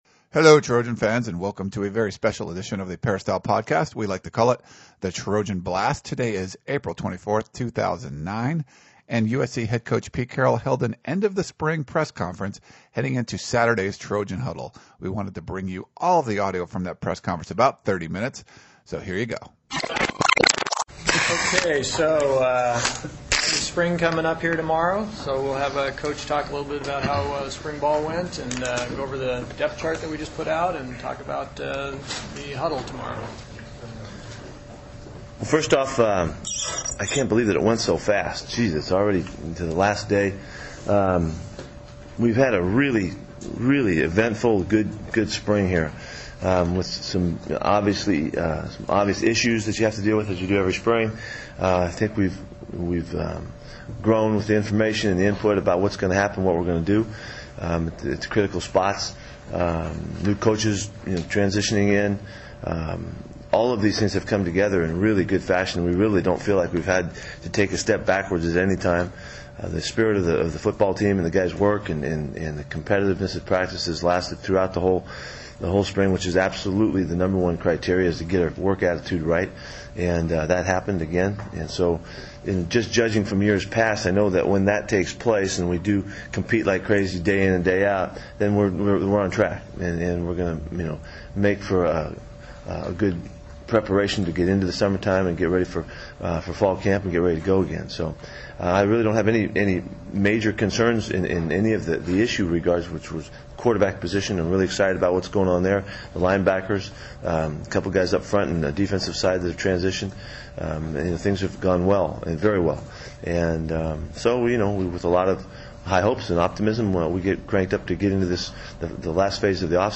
USC head coach Pete Carroll held his annual end of the spring press conference on Friday afternoon, the day before the Trojan Huddle spring game. Carroll addressed the media for about 30 minutes talking about how his team progressed and the newly released depth chart.
Inside: Audio from the Carroll presser.